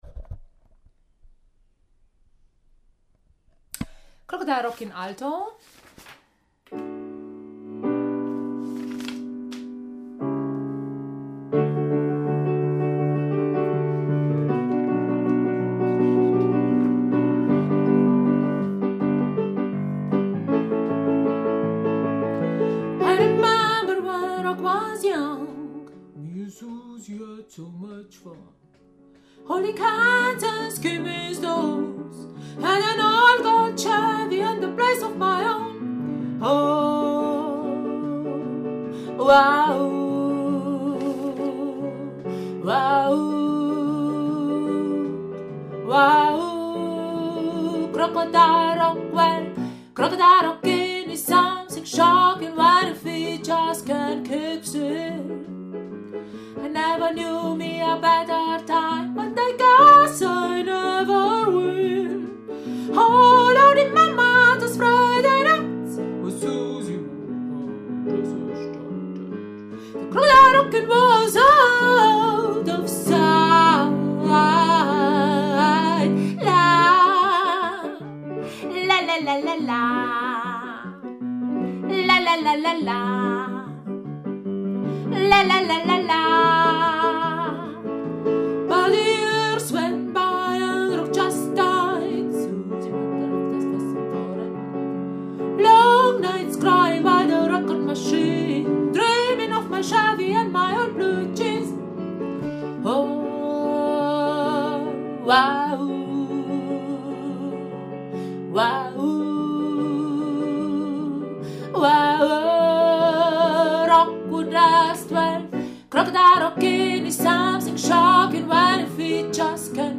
Crocodile-Rock-Alto.mp3